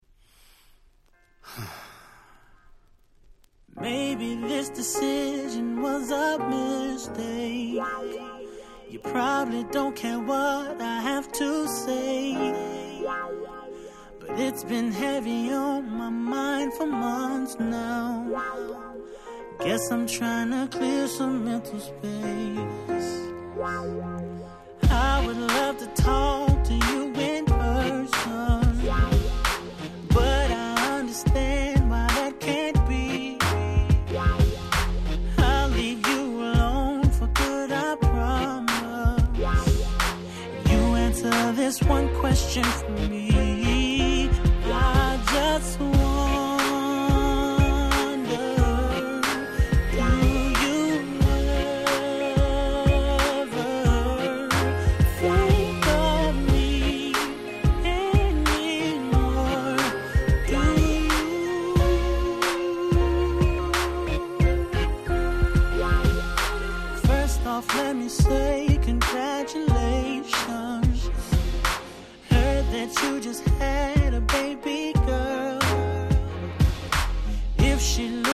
07' Super Hit R&B LP !!